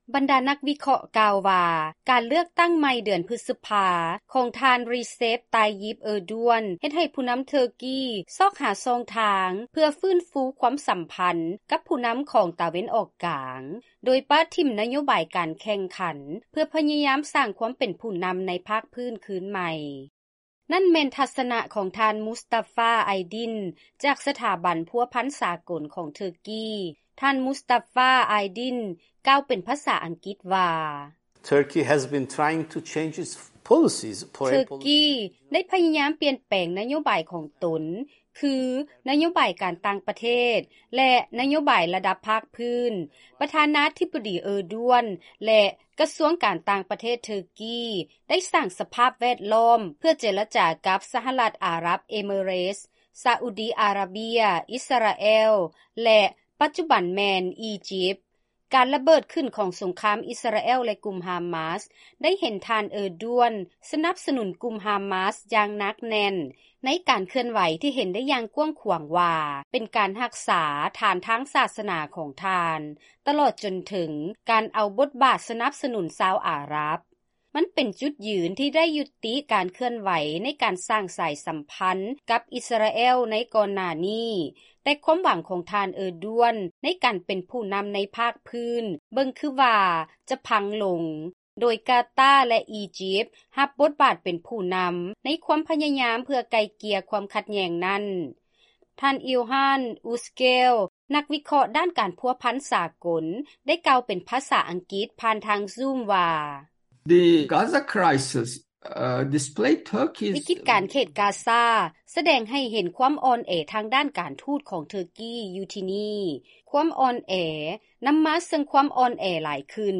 ເຊີນຮັບຟັງລາຍງານ ກ່ຽວກັບປະທານາທິບໍດີ ເອີດວນ ຂອງເທີກີ ໃຊ້ອຳນາດການເລືອກຕັ້ງຄັ້ງໃໝ່ ເພື່ອໃຫ້ເປັນຜູ້ນໍາລະດັບພາກພື້ນ